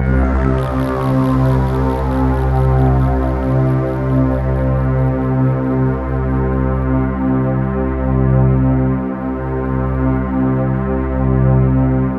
POLYSYNTC2-L.wav